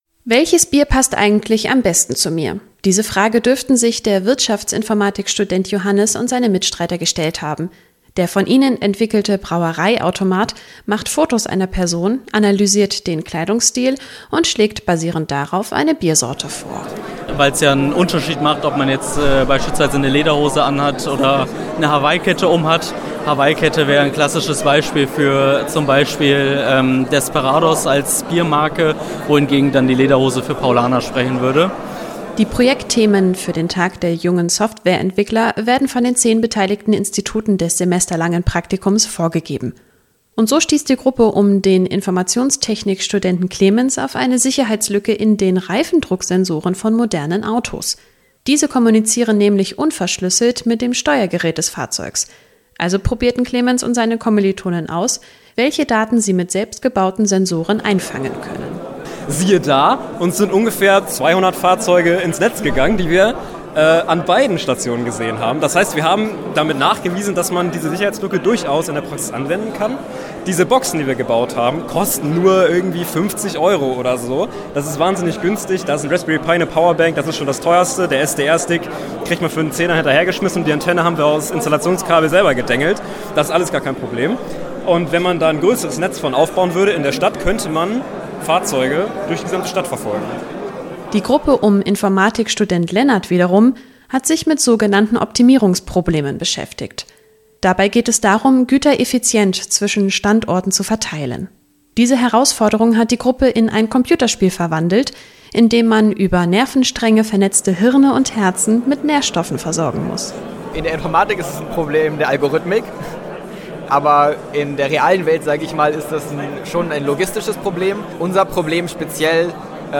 Jedes Jahr zeigen Studierende der TU Braunschweig beim Tag der jungen Softwareentwickler, was sie während ihres Praktikums geschaffen haben. Sie bauen Bierautomaten, finden Sicherheitslücken in Autos und programmieren Computerspiele. Rund 190 junge Menschen stellten gestern im Foyer des Informatikzentrums insgesamt 33 Projekte vor.